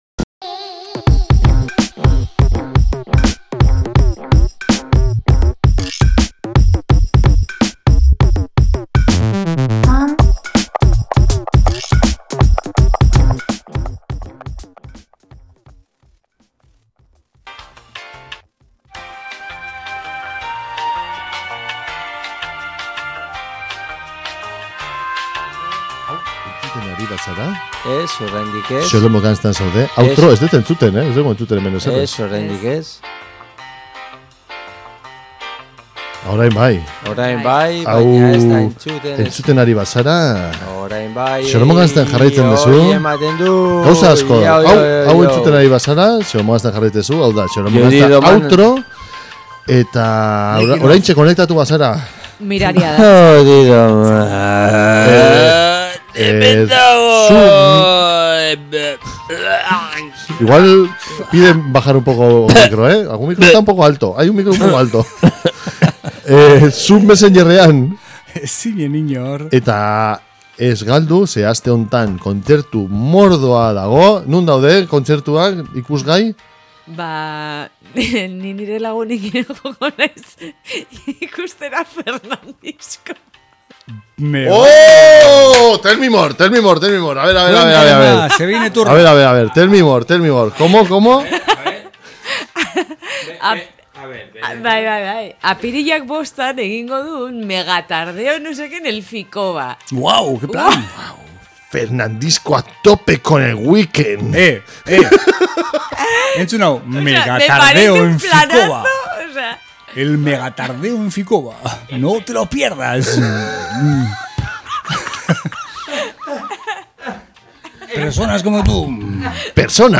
Mundu osoko Rapa, entrebistak zuzenean, Bass doinuak eta txorrada izugarriak izango dituzue entzungai saio honetan.